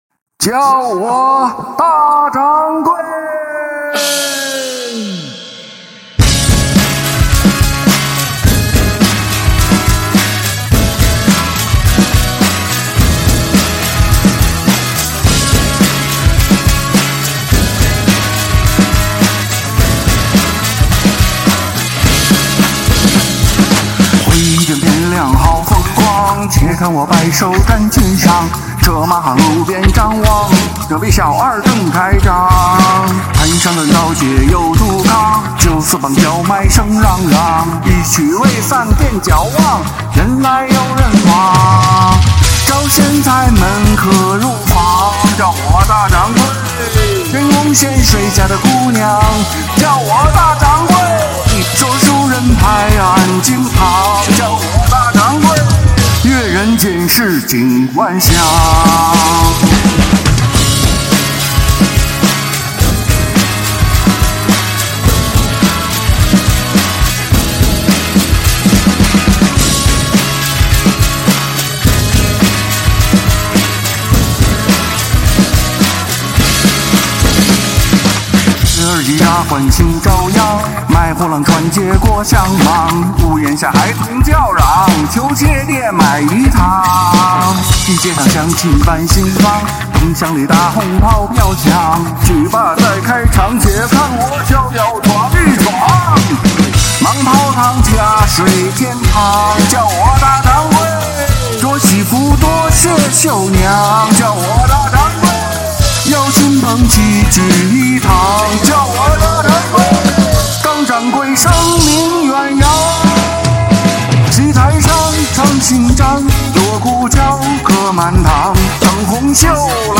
[翻唱] 【花潮贺岁】破五开张啦！